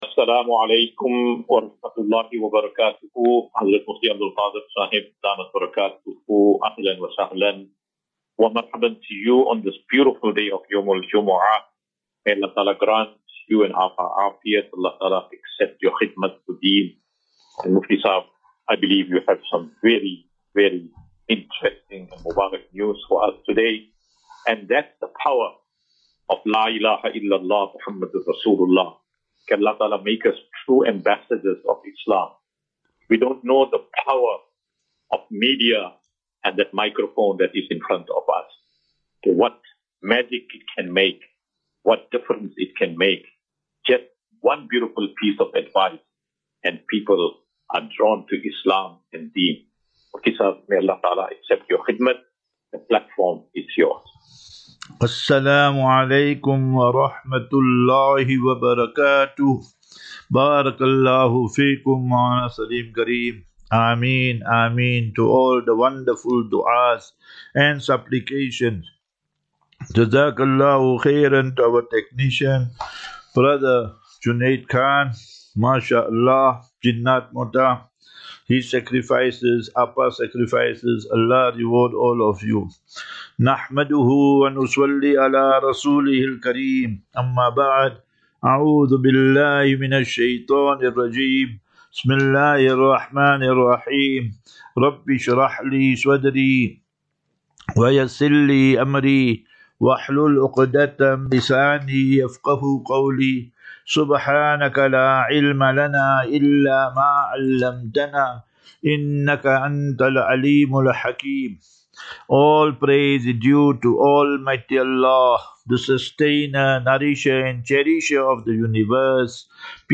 20 Jun 20 June 2025. Assafinatu - Illal - Jannah. QnA